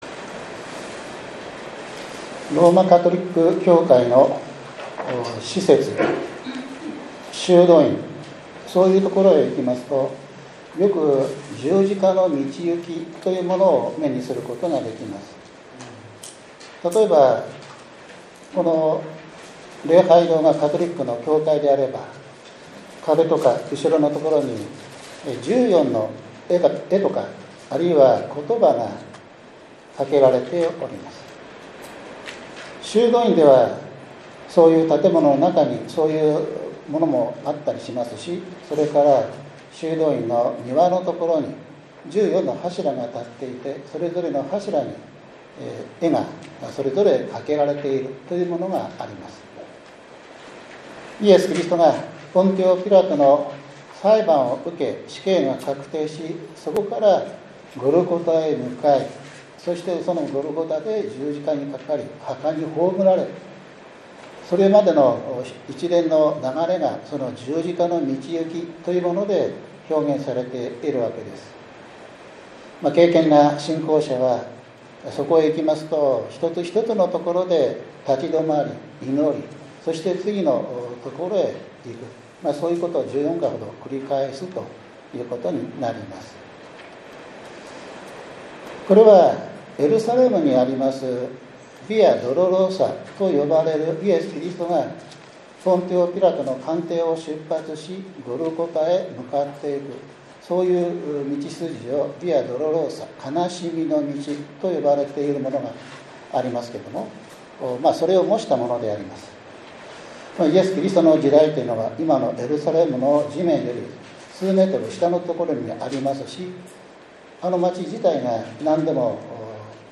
主日礼拝